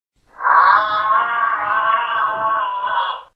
Jerapah_Suara.ogg